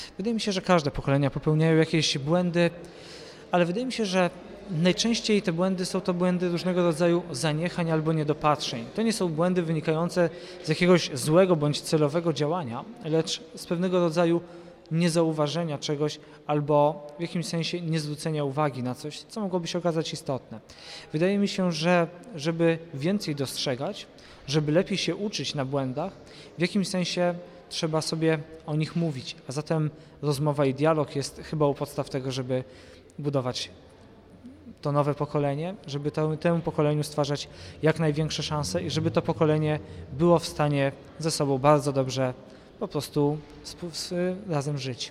Forum to również okazja do spotkań i rozmów z "dorosłymi" liderami, tymi, którzy odnieśli sukces.
Co robić, żeby nie popełniać błędów o tym Stanisław Kluza, Przewodniczący Komisji Nadzoru Bankowego.